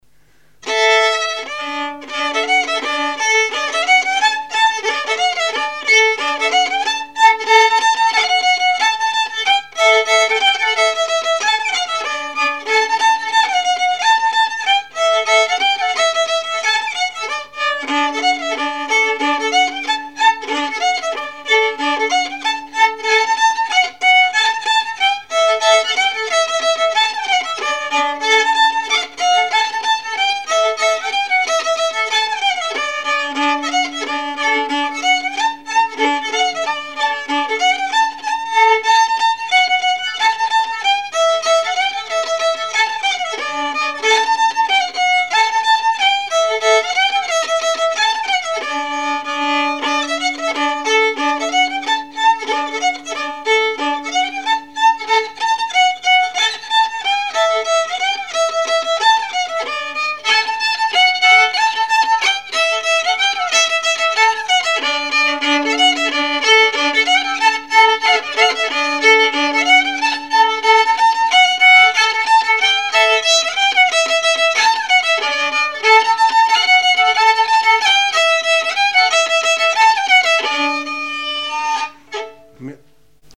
Chants brefs - A danser
danse : scottich trois pas ; danse : paligourdine
Activité du violoneux
Pièce musicale inédite